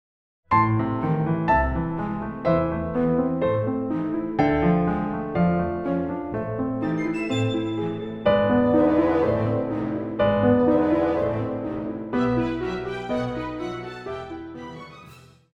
古典
钢琴
管弦乐团
钢琴曲,演奏曲,教材
独奏与伴奏
有主奏
有节拍器